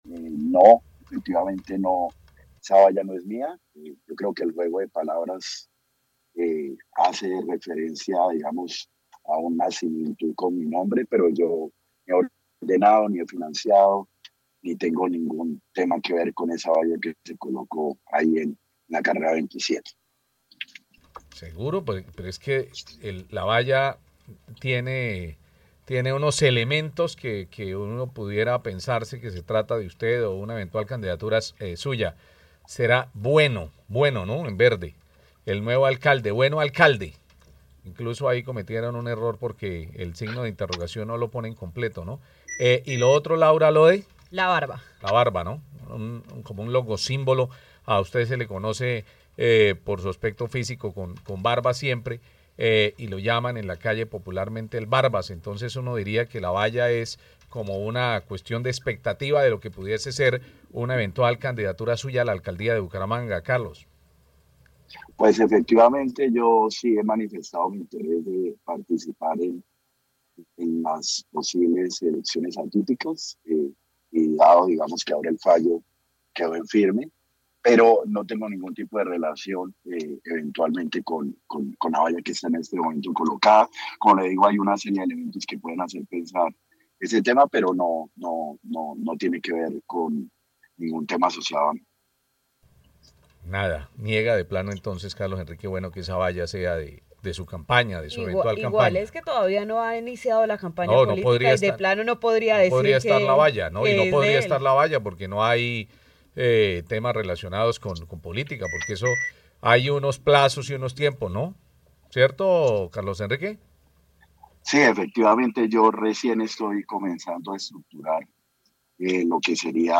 Carlos Bueno, ex director de Tránsito
En entrevista con Caracol Radio, el señor Bueno precisó que la valla de la carrera 27 no es suya.